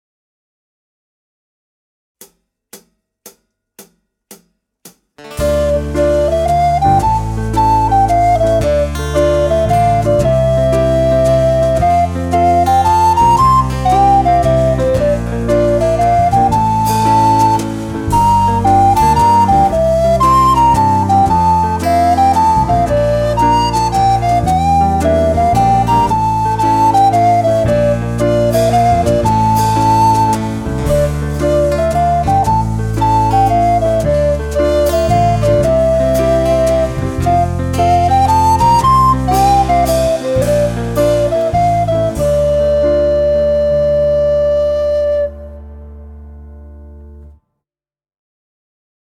Gattung: Altblockflöte und Klavier
9 mittelschwere poppig-rockige Stücke
• Gesamteinspielung der Stücke (mit Band)